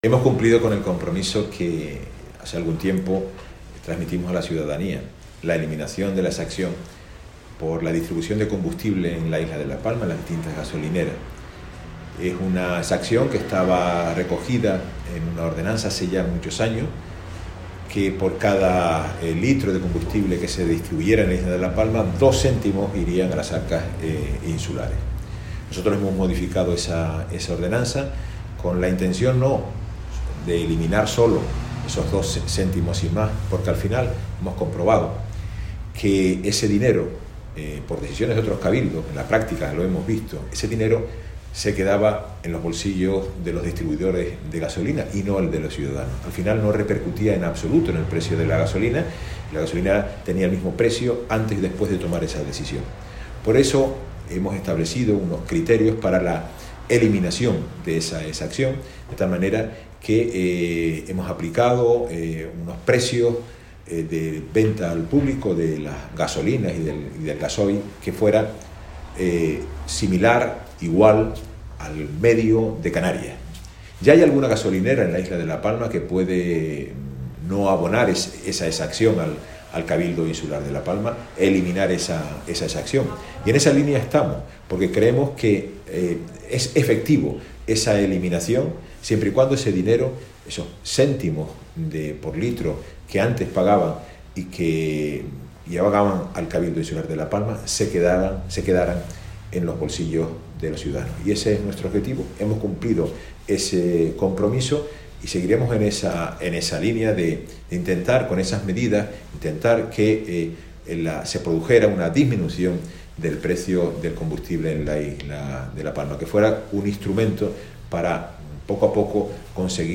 Declaraciones audio Carlos Cabrera Gasolina.mp3